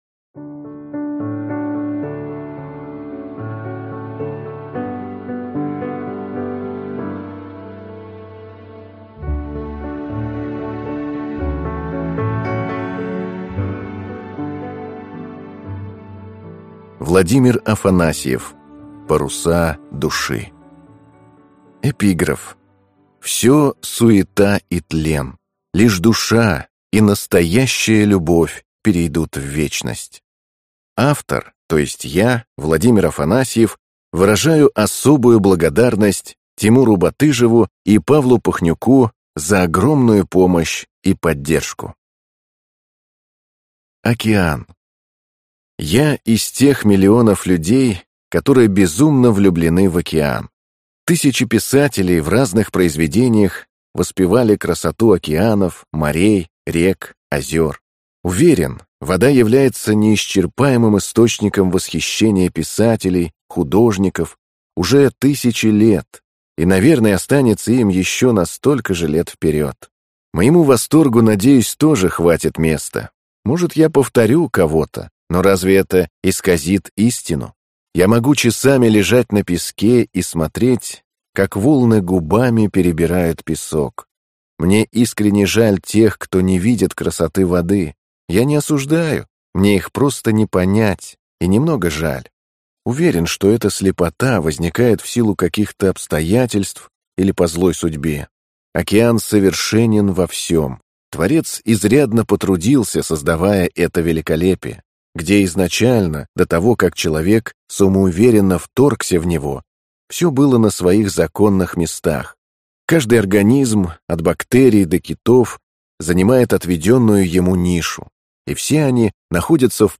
Аудиокнига Паруса души | Библиотека аудиокниг